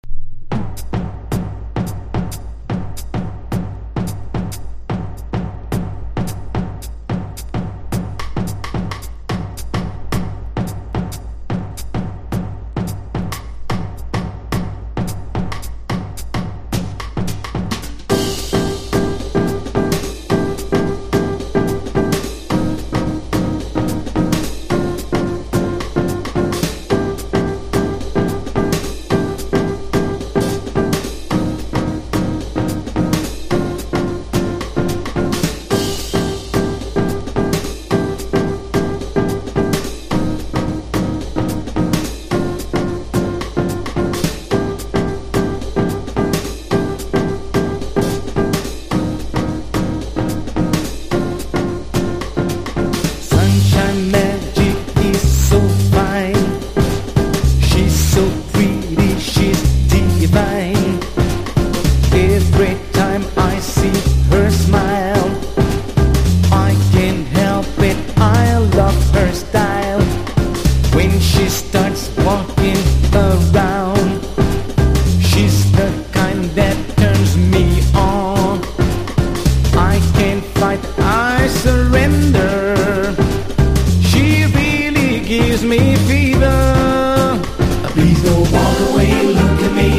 CROSSOVER / LOUNGE# BREAK BEATS / BIG BEAT
ラウンジ、ハウス、ジャズなど、様々ダンス･ミュージックやチル･アウト･サウンドを聴かせます。